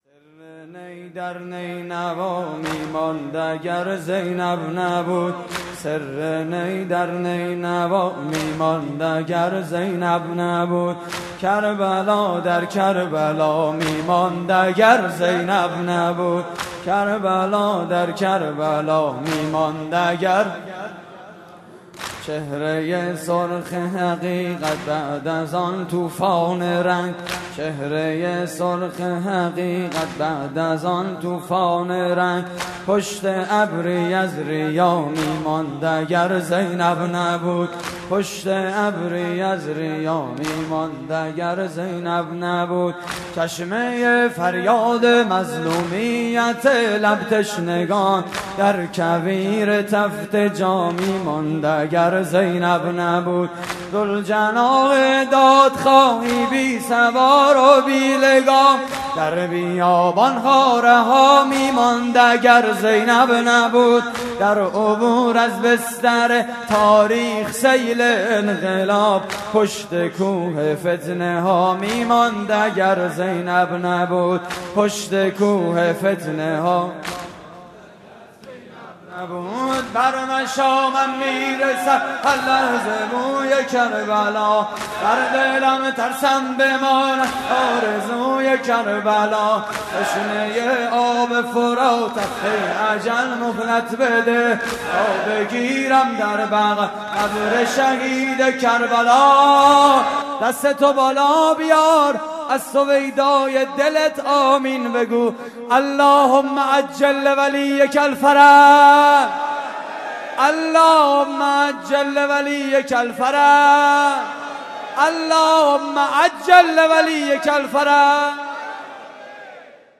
صوت مراسم: